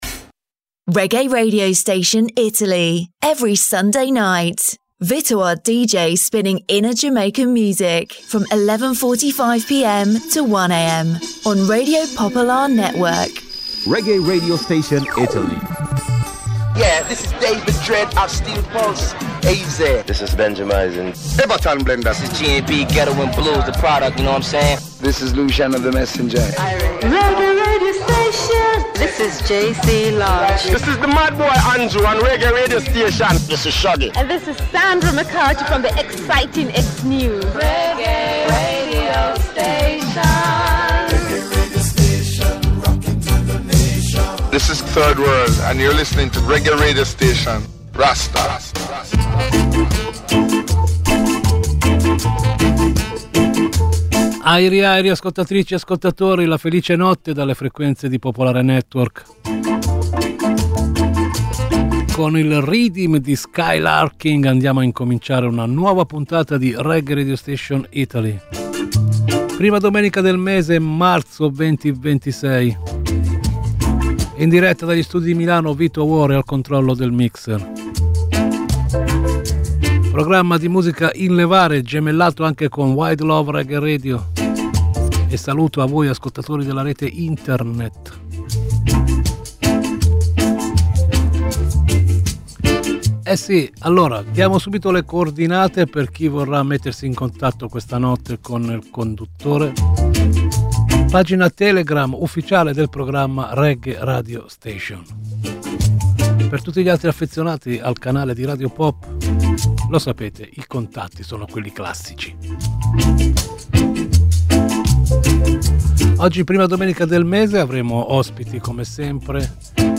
A ritmo di Reggae